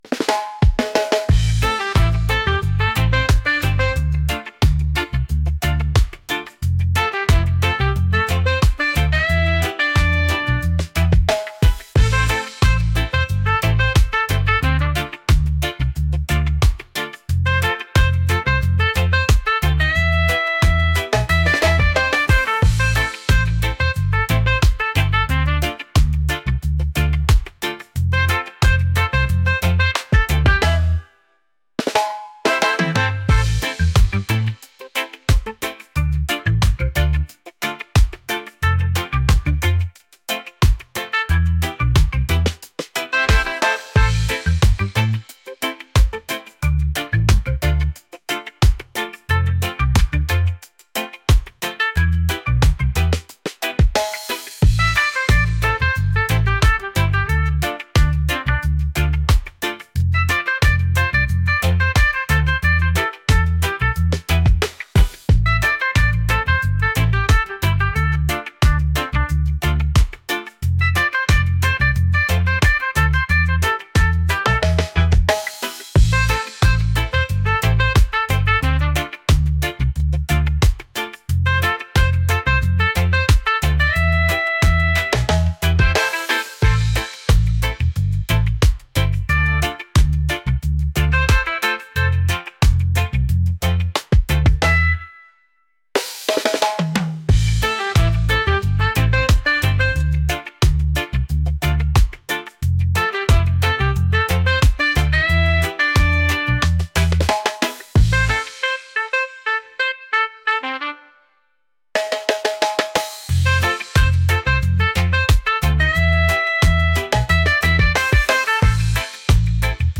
reggae | funky | upbeat